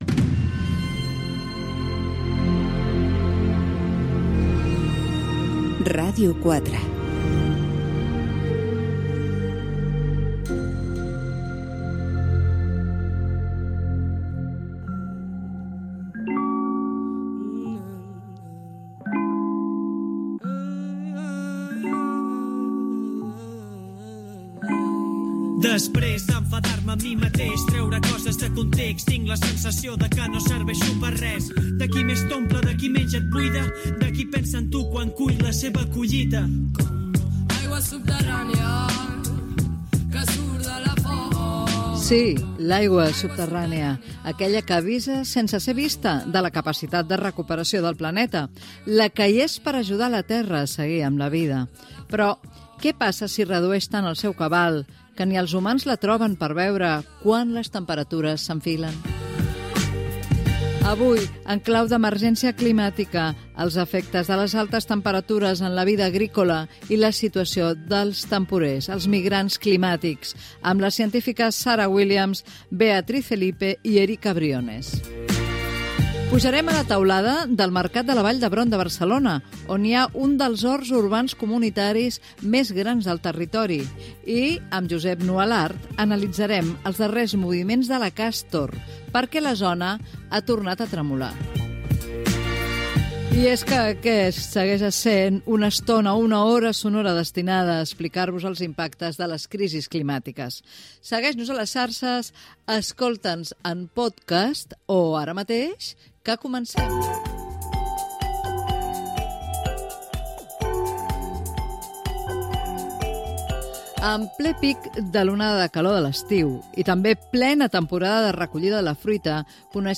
Indicatiu de l'emissora, presentació del programa, sumari i informació de la mort d'un temporer de la fruita.
Divulgació
FM